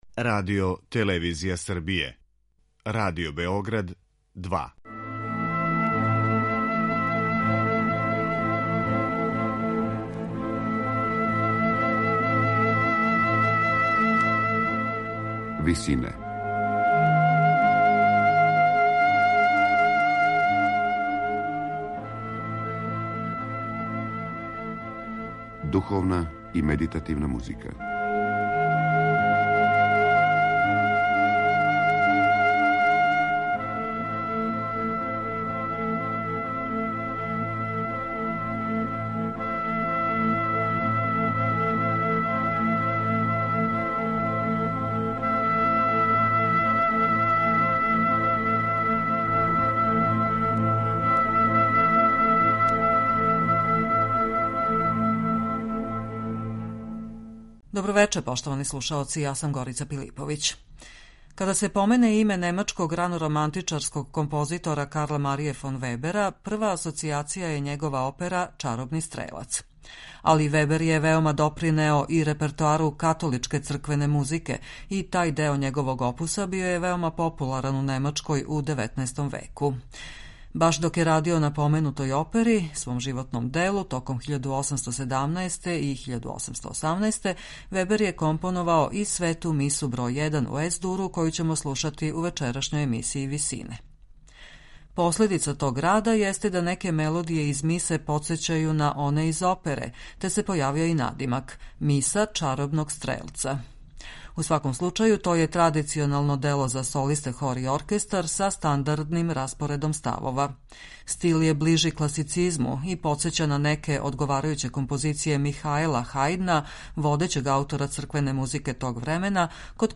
Баш док је радио на поменутој опери, свом животном делу, током 1817. и 1818, Вебер је компоновао и Свету мису бр. 1 у Ес-дуру. Последица тог рада јесте да неке мелодије из мисе подсећају на оне из опере, те се појавио и надимак - миса чаробног стрелца. У сваком случају, то је традиционално дело за солисте, хор и оркестар, са стандардним распоредом ставова. Стил је ближи класицизму и подсећа на неке одговарајуће композиције Михаела Хајдна, водећег аутора црквене музике тог времена, код којег је Вебер студирао у последњим годинама XVIII века.